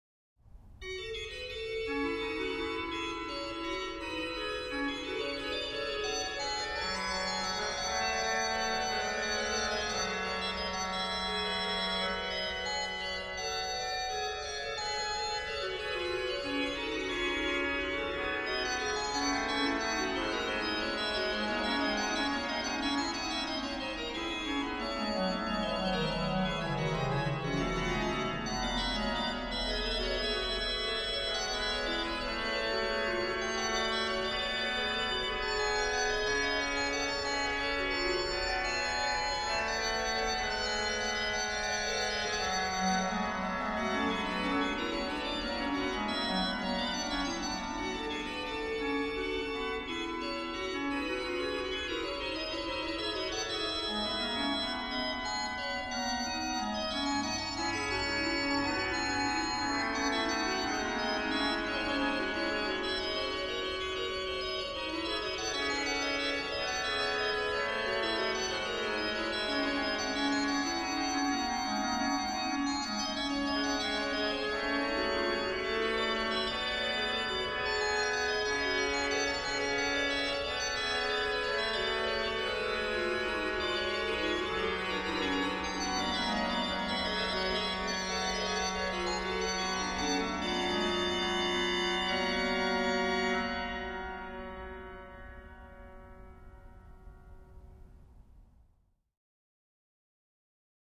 Subtitle   à 2 claviers et pédale
lh: HW: Rfl8, Qnt3, Oct2
Ped: Tr8